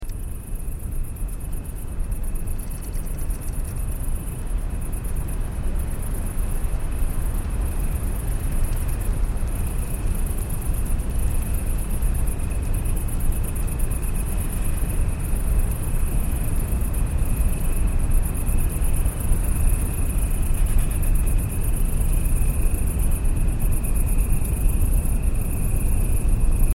ADHD calming frequencies to soothe